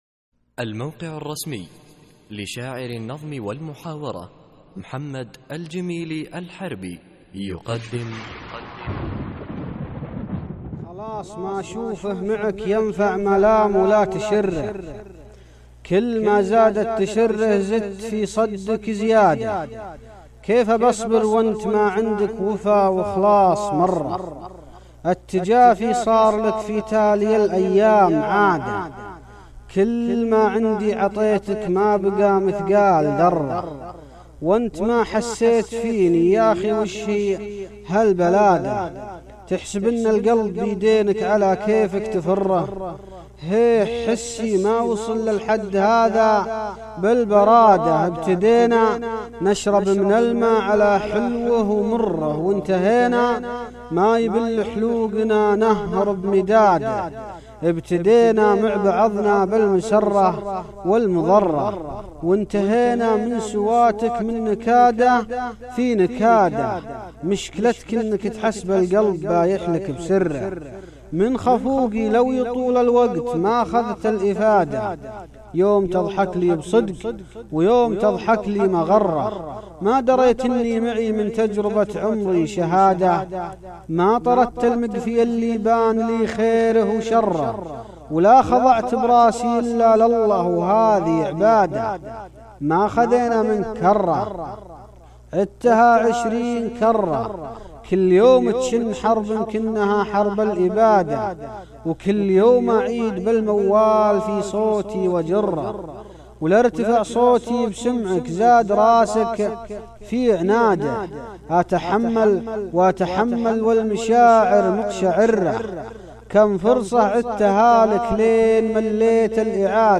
القصـائــد الصوتية
اسم القصيدة : تجربة عمري ~ إلقاء